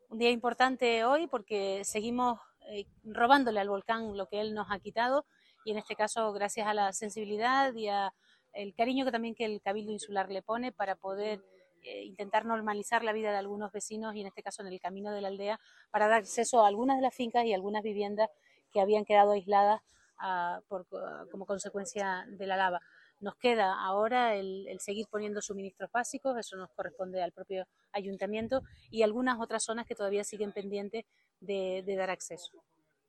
Por su parte, la alcaldesa de Los Llanos de Aridane, Noelia García, destaca la coordinación que ha habido entre su Ayuntamiento y el Cabildo para salvar esta zona aislada por las coladas.